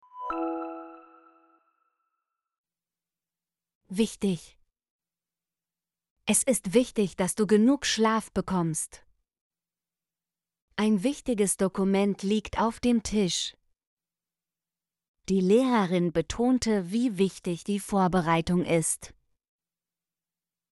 wichtig - Example Sentences & Pronunciation, German Frequency List